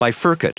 Prononciation du mot bifurcate en anglais (fichier audio)
Prononciation du mot : bifurcate